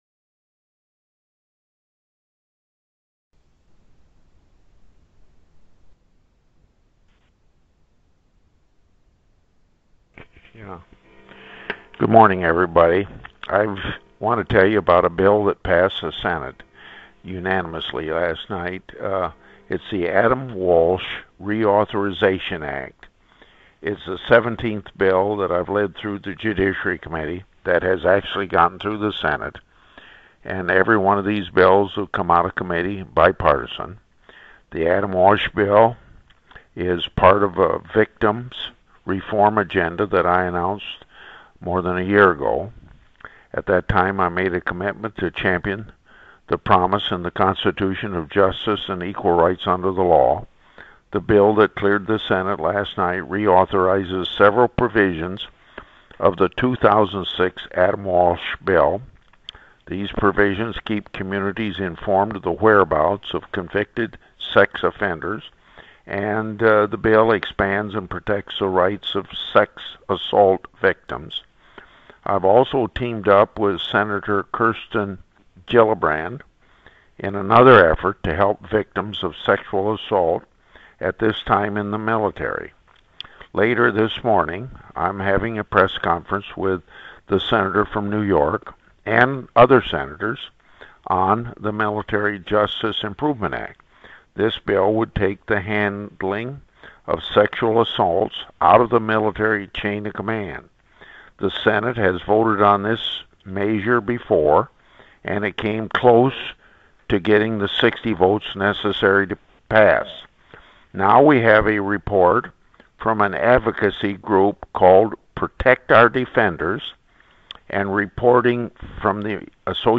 Grassley Conference Call with Iowa Radio Networks